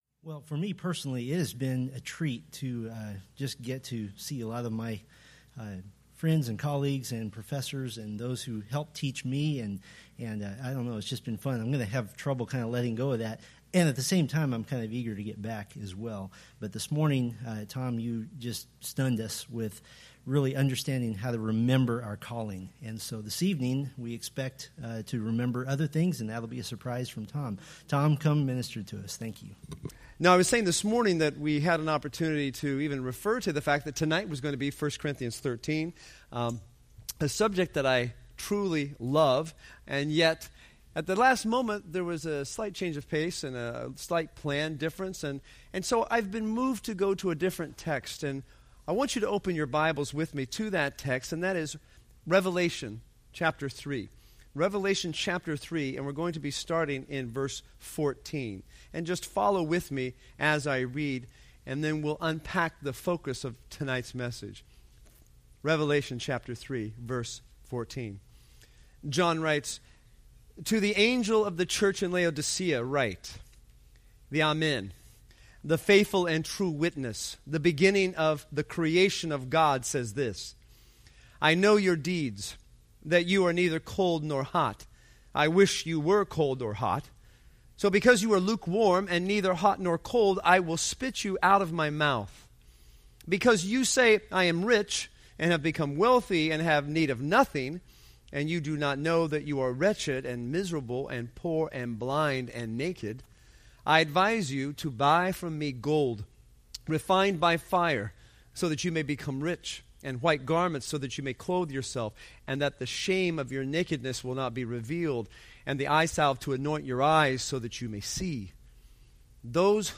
Selected Scriptures Sermon Series